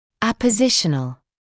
[ˌæpə’zɪʃənl][ˌэпэ’зишэнл]методом формирования новых слоев (напр., кости)